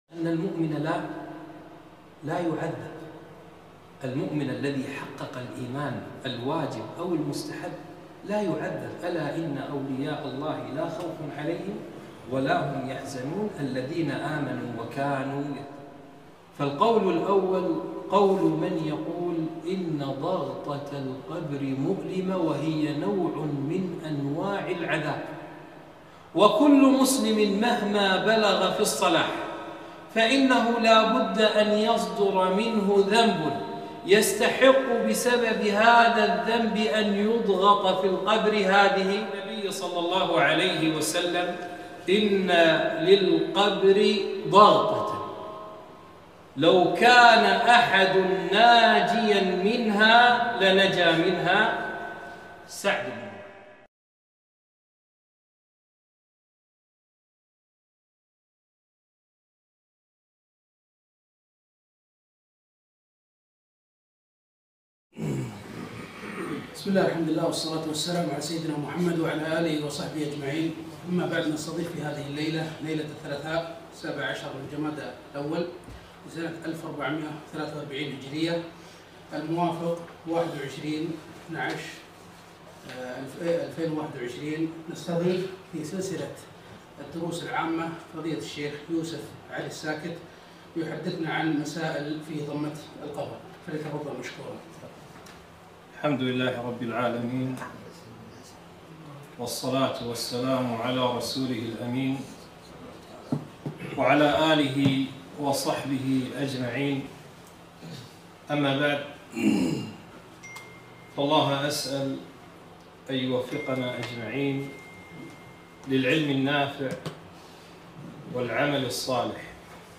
محاضرة - مسائل في ضمة القبر - دروس الكويت